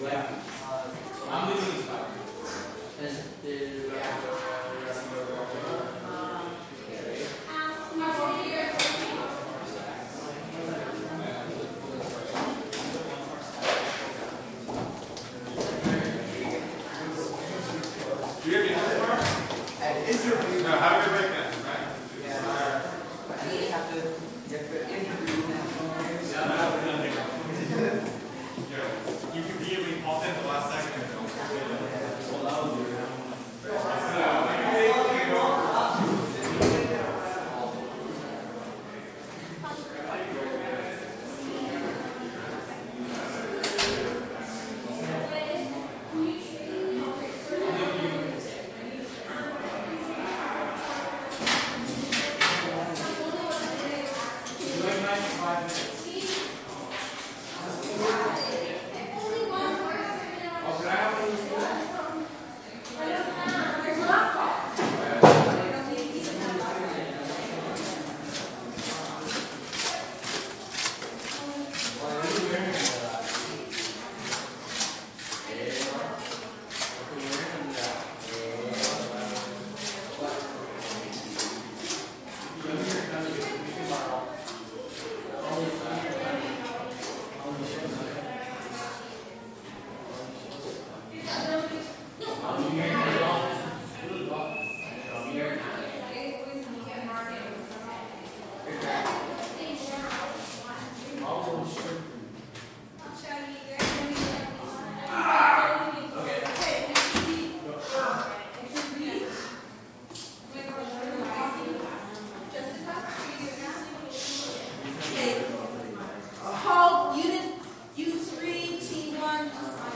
蒙特利尔 " 人群中餐厅繁忙的早午餐开放回声 Eggspectations 蒙特利尔，加拿大
描述：人群int中等餐馆繁忙的早午餐开放回声Eggspectations蒙特利尔，Canada.flac
标签： 蒙特利尔 开放 回声 INT 餐厅 人群 加拿大
声道立体声